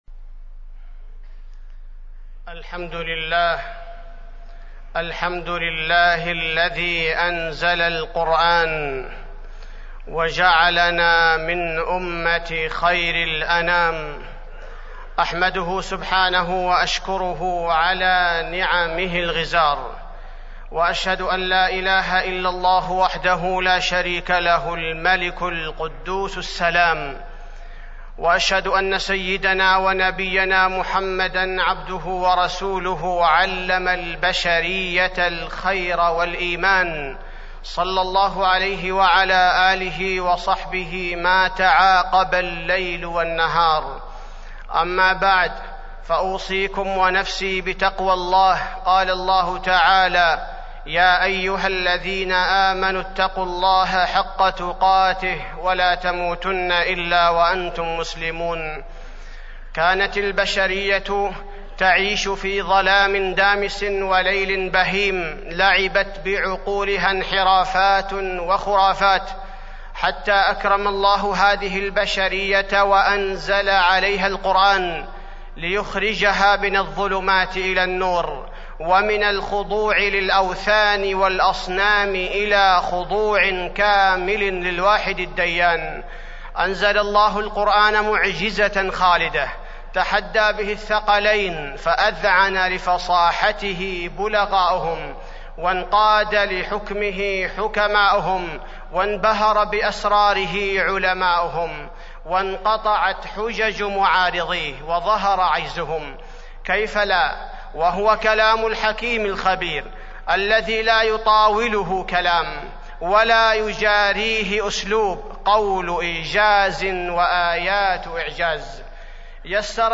تاريخ النشر ١٢ ربيع الثاني ١٤٢٦ هـ المكان: المسجد النبوي الشيخ: فضيلة الشيخ عبدالباري الثبيتي فضيلة الشيخ عبدالباري الثبيتي فضل القرآن الكريم